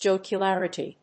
/dʒὰkjʊlˈærəṭi(米国英語)/